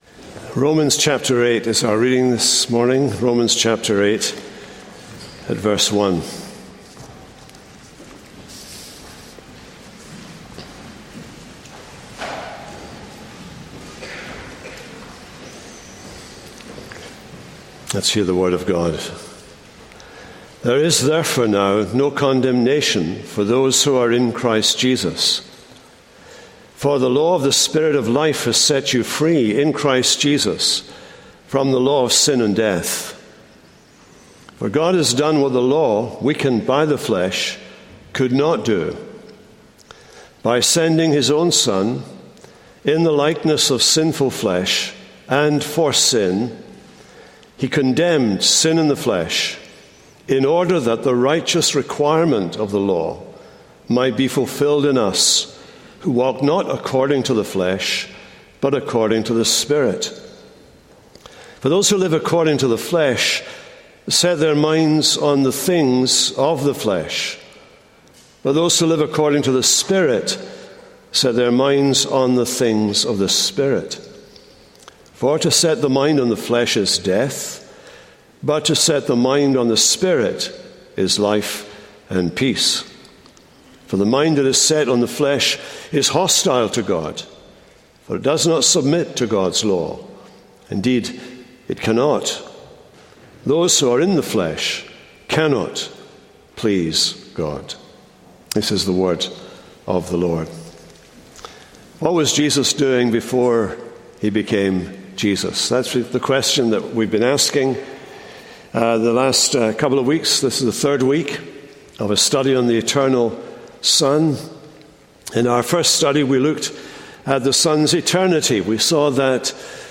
Jesus - Impeccability | SermonAudio Broadcaster is Live View the Live Stream Share this sermon Disabled by adblocker Copy URL Copied!